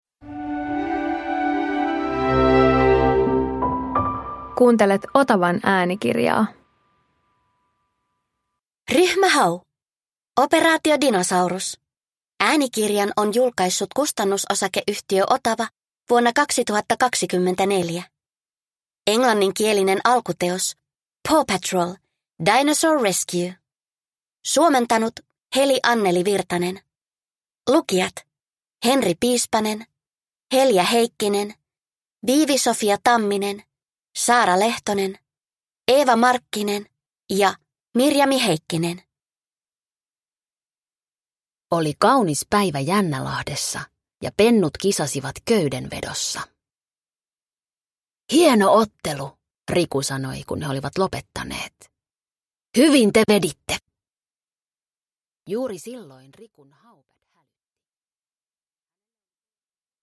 Ryhmä Hau - Operaatio Dinosaurus – Ljudbok